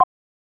sliderbar.wav